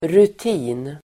Uttal: [rut'i:n]